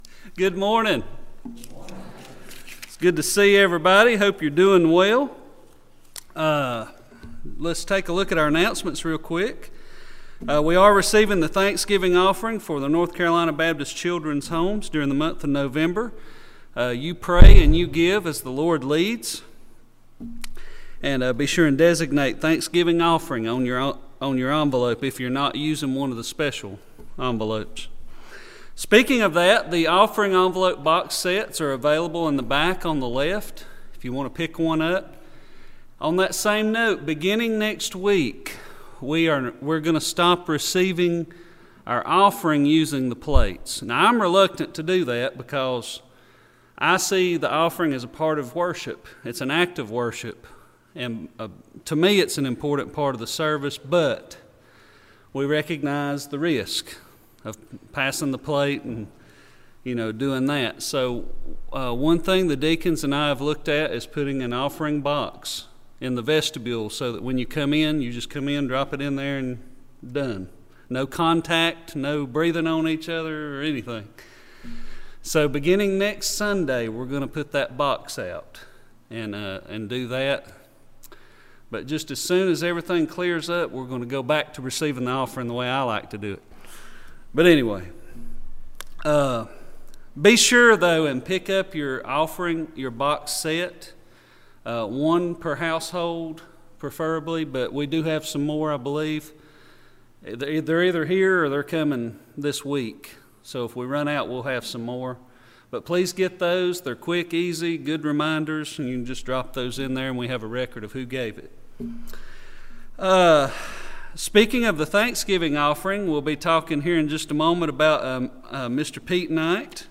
10:00am Worship Service CLICK HERE to Listen (Audio Only)